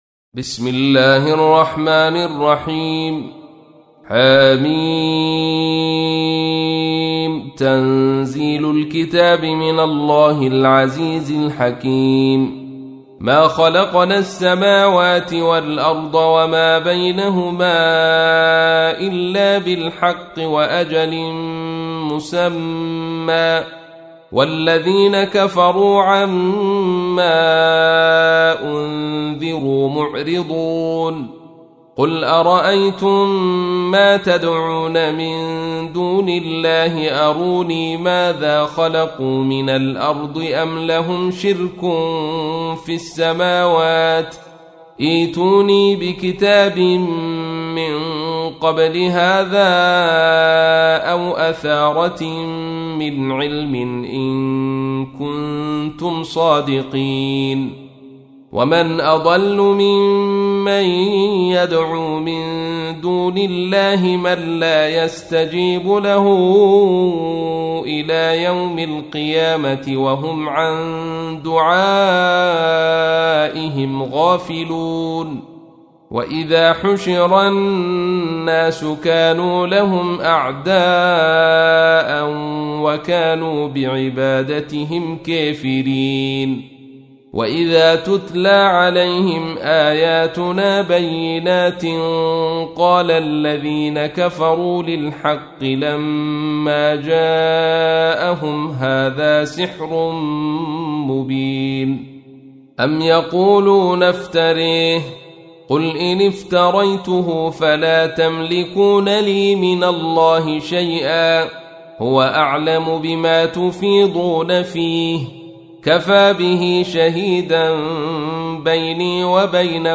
تحميل : 46. سورة الأحقاف / القارئ عبد الرشيد صوفي / القرآن الكريم / موقع يا حسين